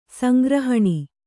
♪ sangrahaṇi